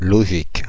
Ääntäminen
Synonyymit rationnel cohérent Ääntäminen France (Paris): IPA: /lɔ.ʒik/ France: IPA: [yn lɔ.ʒik] Paris: IPA: [lɔ.ʒik] Haettu sana löytyi näillä lähdekielillä: ranska Käännös Substantiivit 1. логика Suku: f .